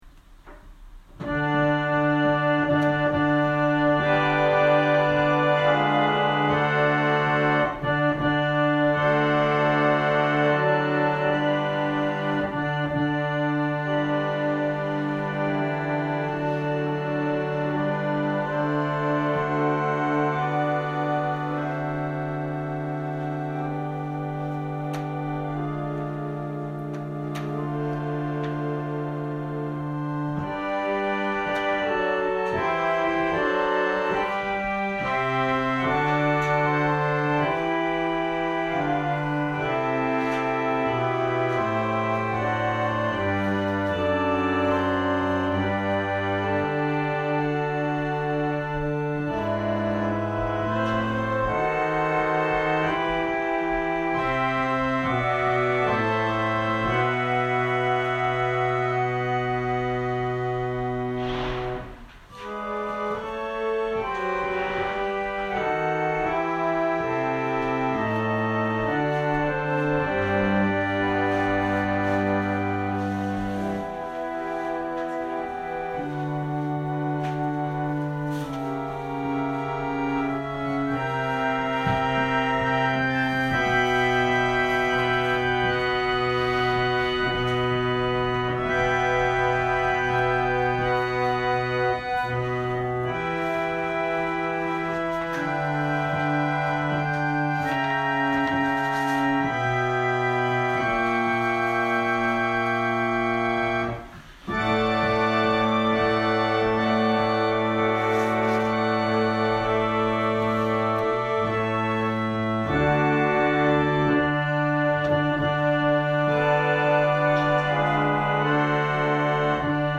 千間台教会。説教アーカイブ。
音声ファイル 礼拝説教を録音した音声ファイルを公開しています。